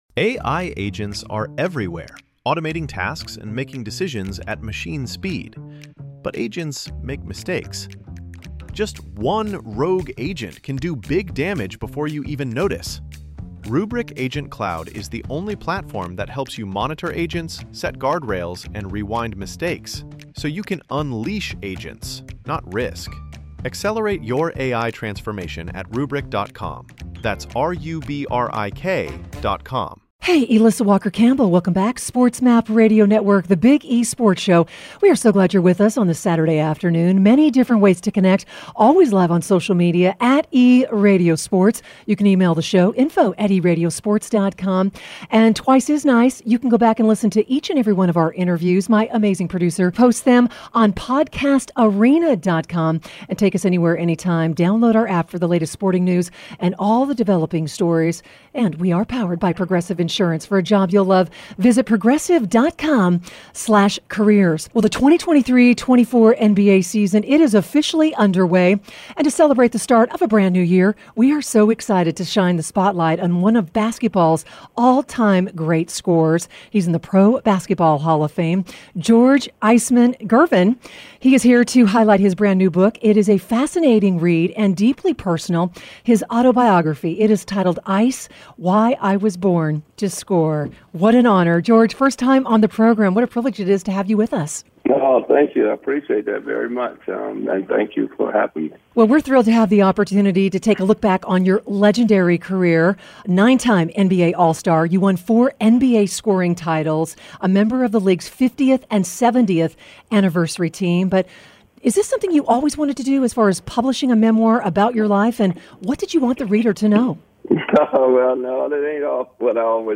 NBA Hall of Famer George Gervin joins The Big E Sports Show